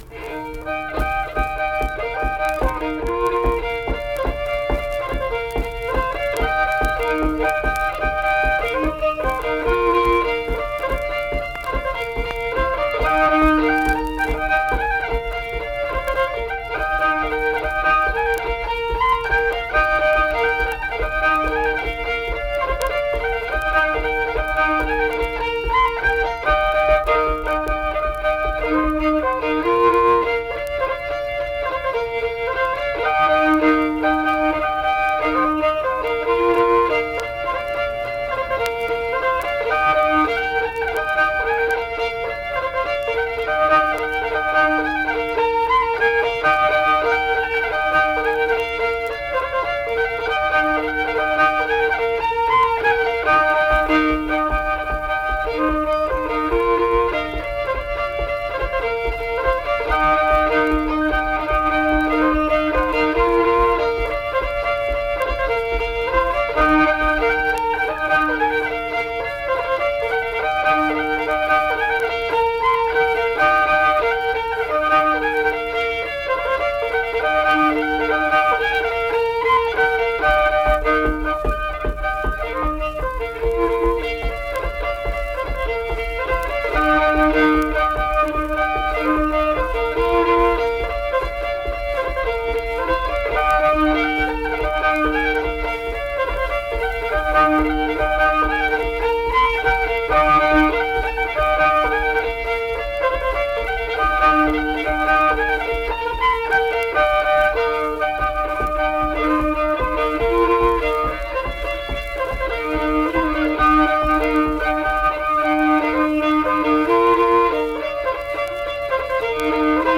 Unaccompanied fiddle music
Instrumental Music
Fiddle
Marlinton (W. Va.), Pocahontas County (W. Va.)